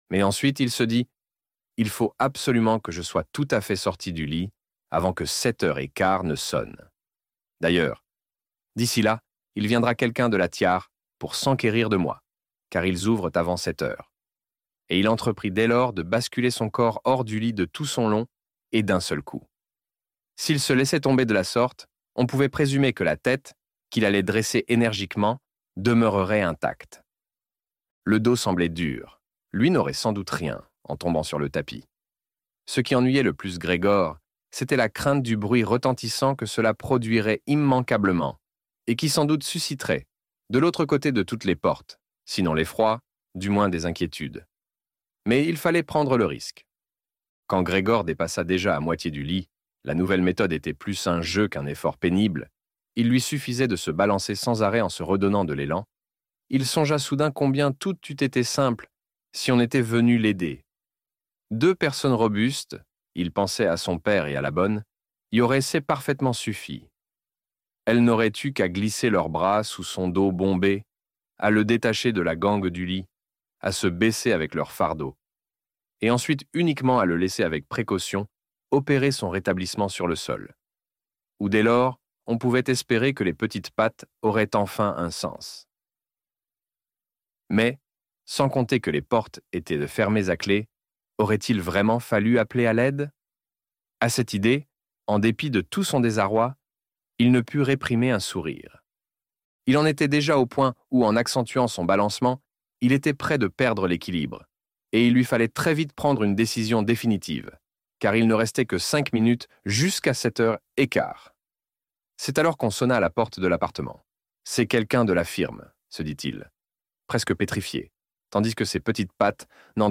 La Métamorphose - Livre Audio